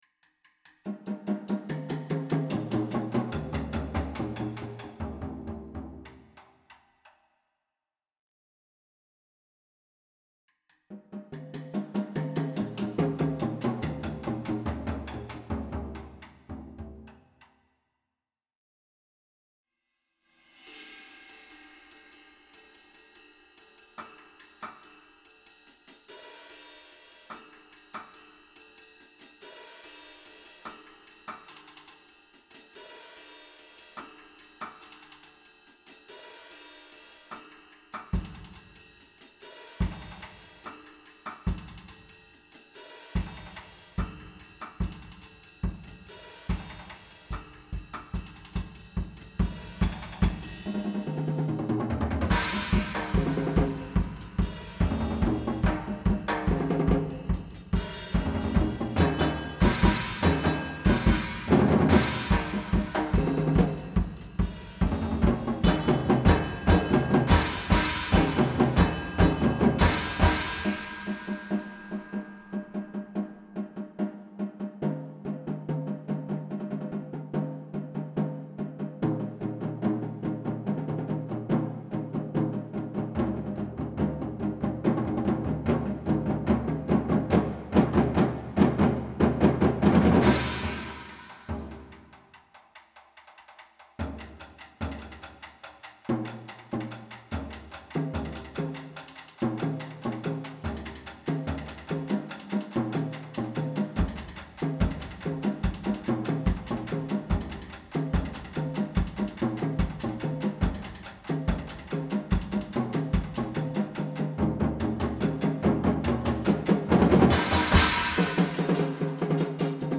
Voicing: Percussion Quartet